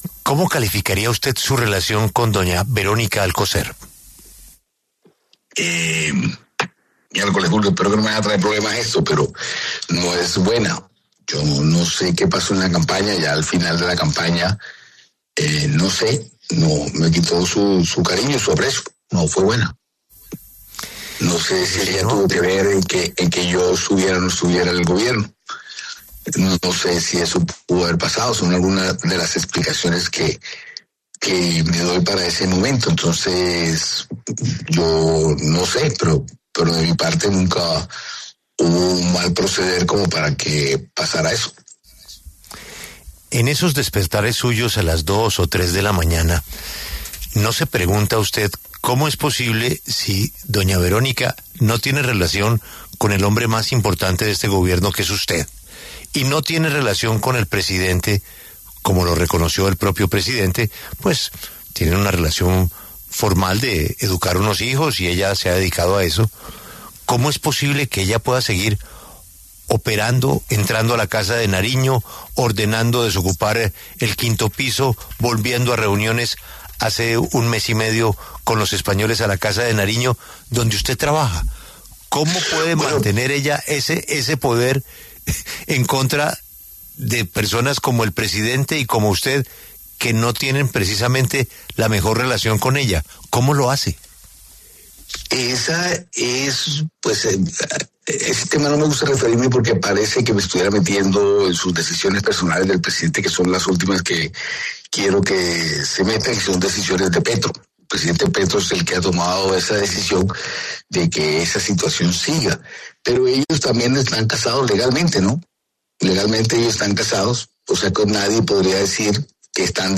Este jueves, 30 de octubre, el ministro del Interior, Armando Benedetti, habló en los micrófonos de La W, con Julio Sánchez Cristo, sobre su inclusión en la Lista Clinton en conjunto con el presidente Gustavo Petro, Nicolás Petro Burgos y Verónica Alcocer.